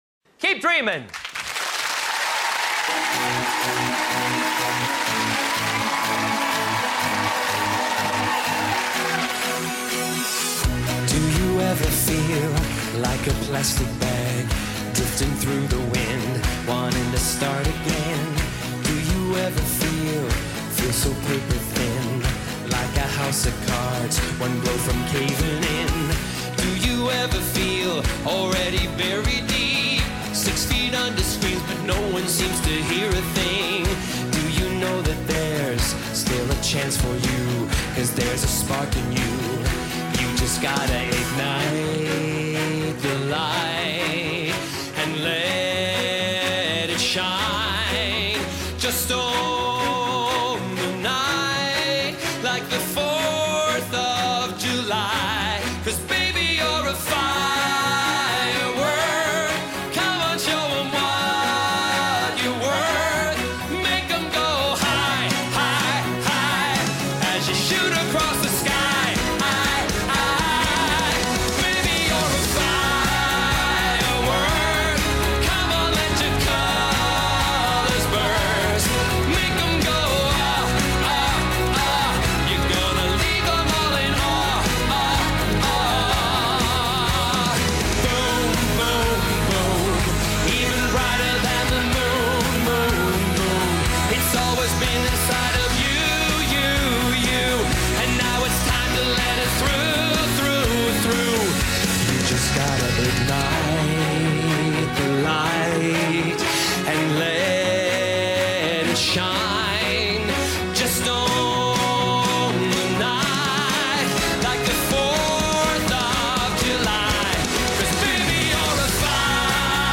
MY FACE WHEN HE STARTED SINGING